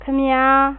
28_I beg your pardon (Male)
28_I-beg-your-pardon-Male.wav